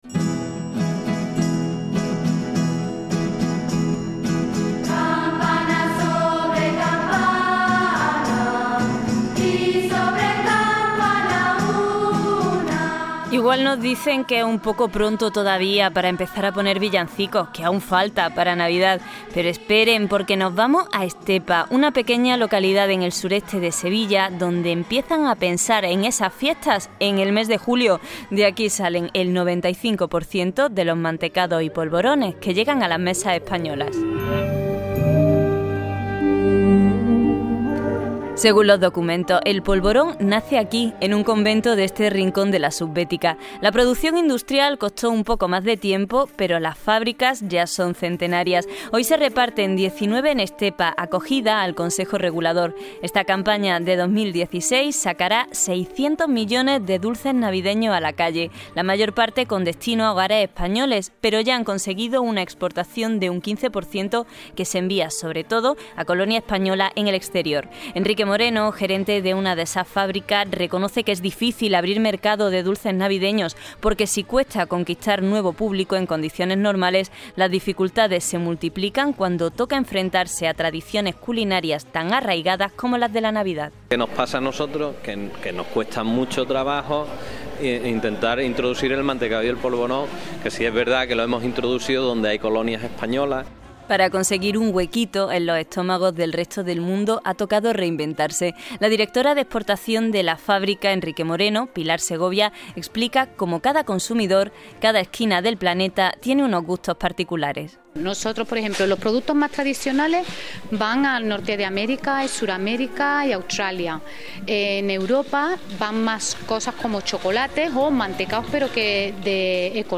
Una radiografía sonora de la situación del sector. Una realidad, dulce, que responde a nuestra industria, cambiante y adaptada a los nuevos tiempos y mercados.